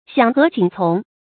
响和景从 xiǎng hé jǐng cóng
响和景从发音